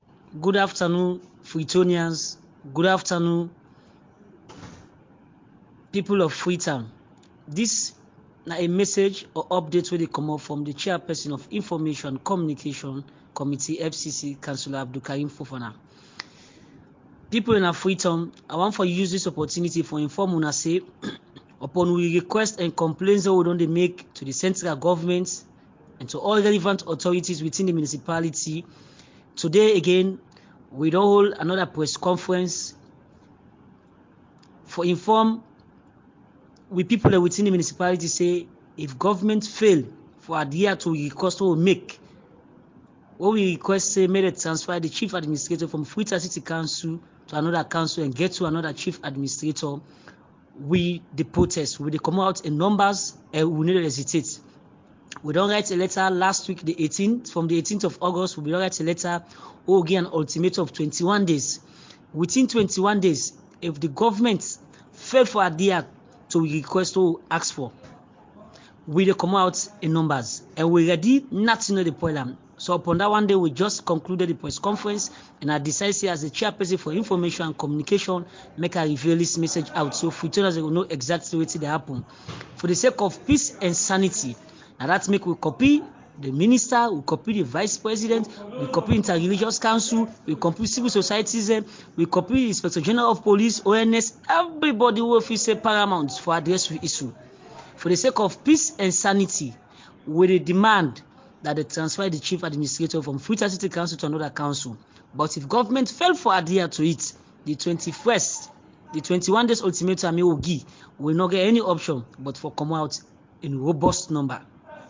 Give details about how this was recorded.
At yesterday’s press conference, the elected councillors warned the government that if their demand for the removal of the chief administrator is not met, they will walk out into the streets in large numbers in protest.